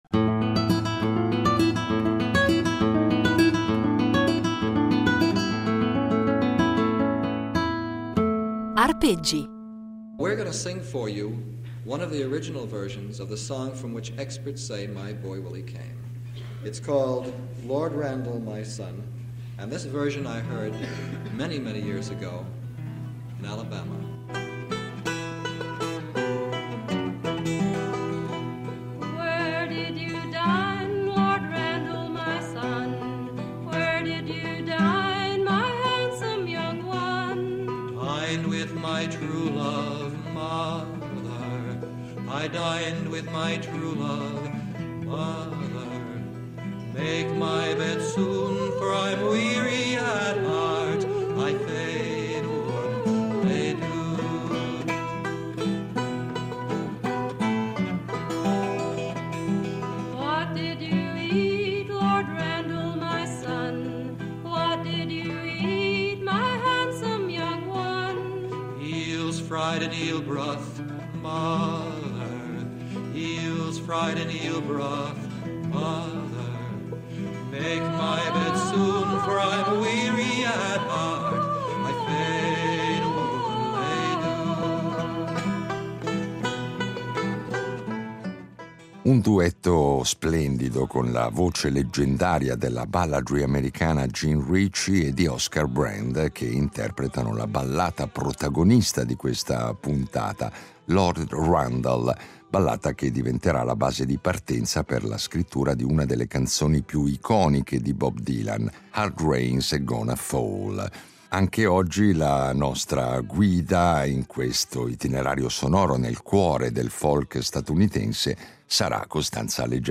Non solo, però, perché i nostri itinerari sonori saranno impreziositi dalle esecuzioni “live”, e quindi inedite, del trio Folkways